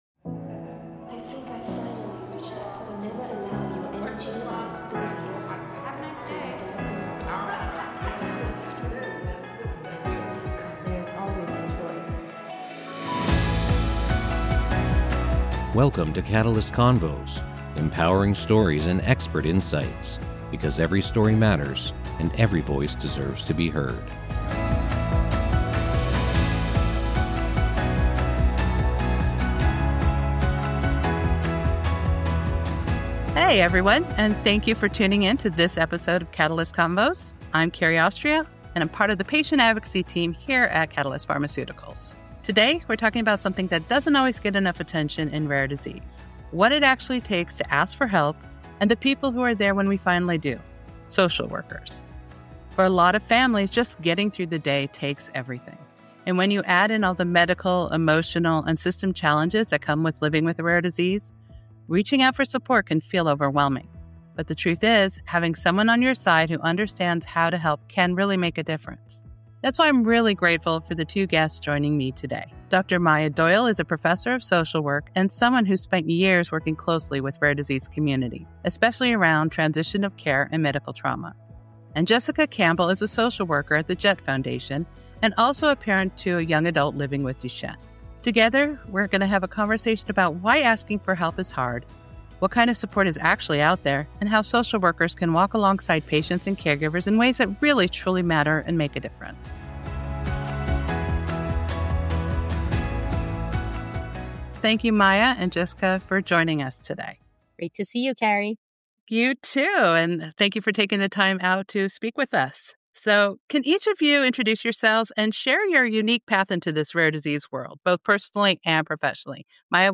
From the emotional weight of caregiving to the system-level barriers patients face, this conversation breaks down misconceptions about what social work is and isn’t. Listeners will also hear about new efforts to train more social workers in the genetics and rare disease space, and how advocacy groups can begin incorporating mental health and social support into their programs.